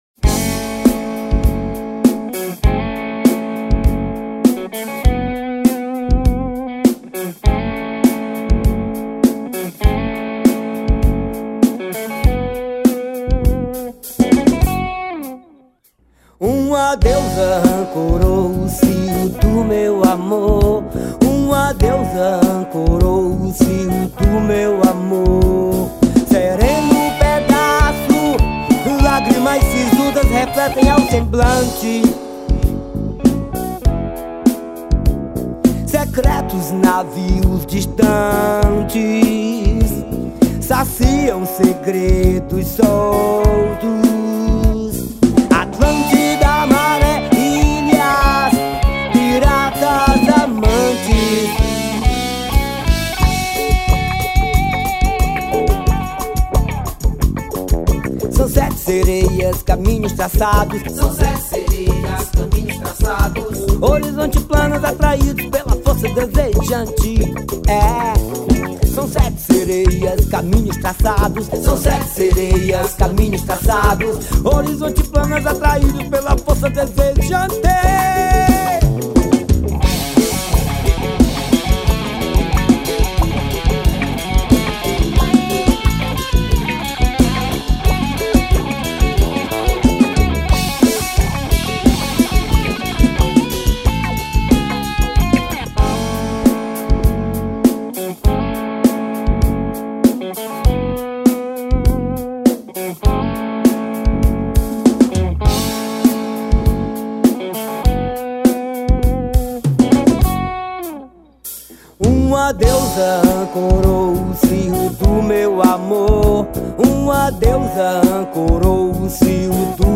2234   03:11:00   Faixa:     Forró